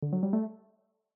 ringtone-B2csBdU4.mp3